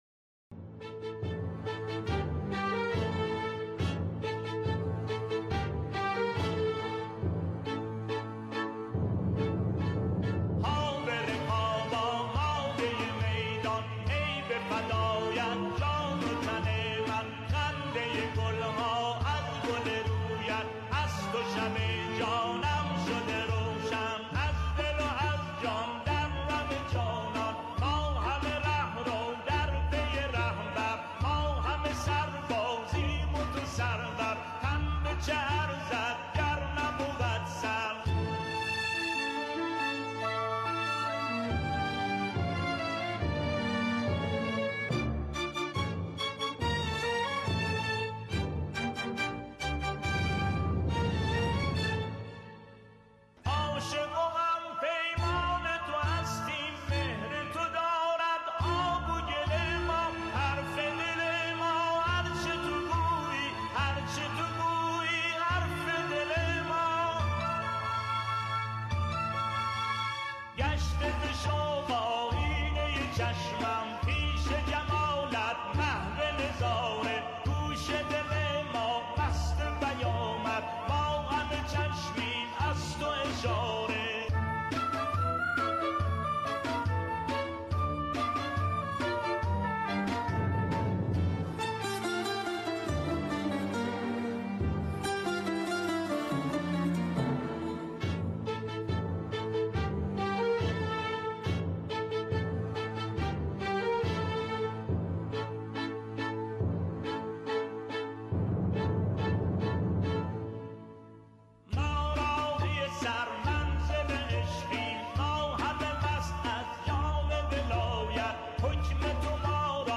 اهنگ